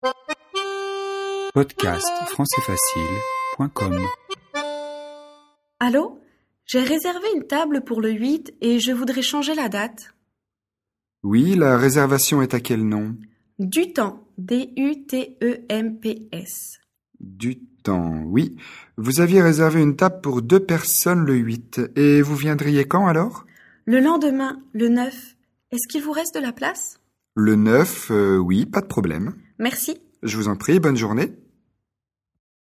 Situation : une cliente appelle le restaurant pour modifier sa réservation.
🔷 DIALOGUE